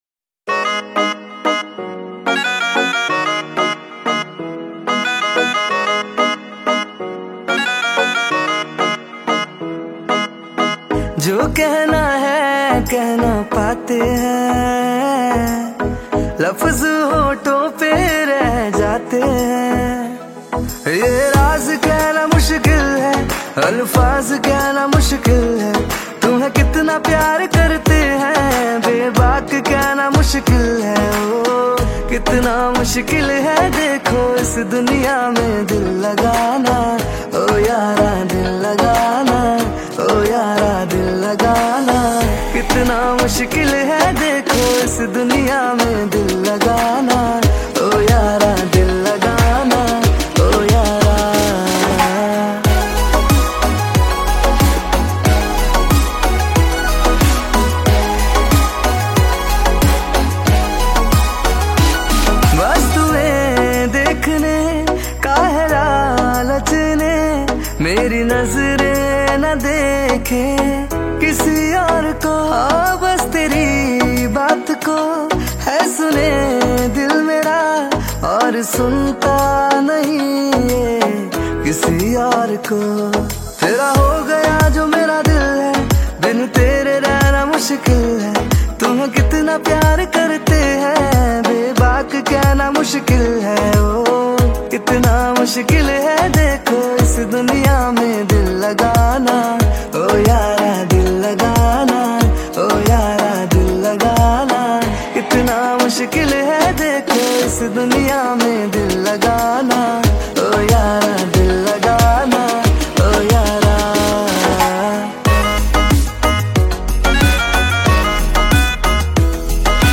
Category New Cover Mp3 Songs 2022 Singer(s